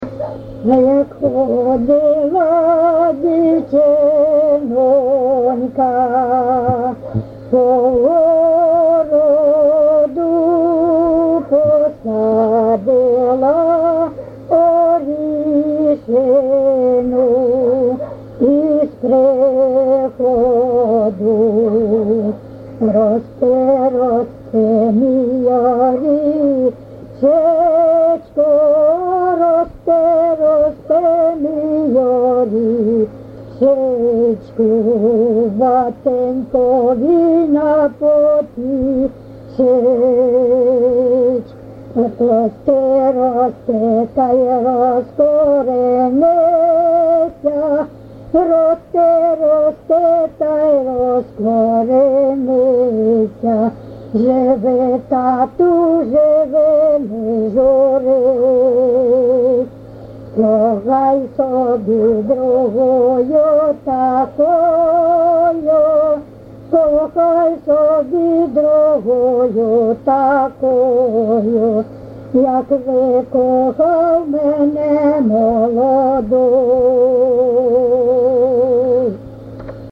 ЖанрВесільні
Місце записус. Коржі, Роменський район, Сумська обл., Україна, Слобожанщина